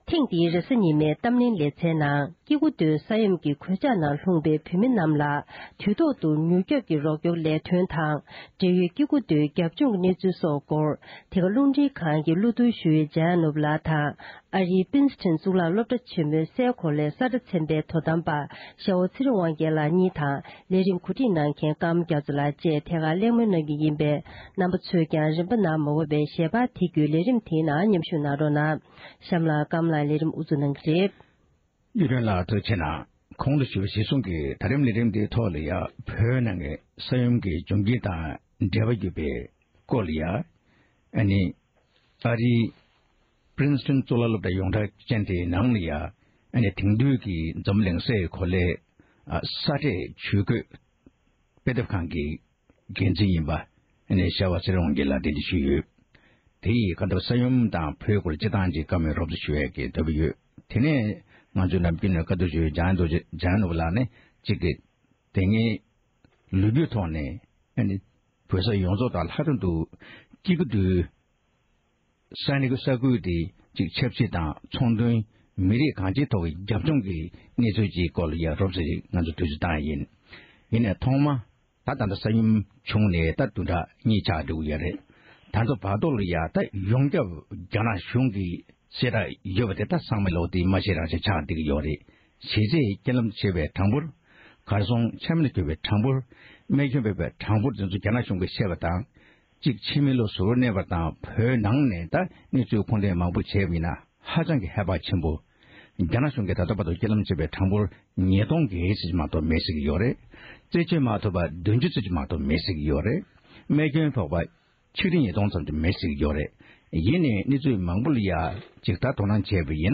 གླེང་མོལ་གནང་བར་གསན་རོགས༎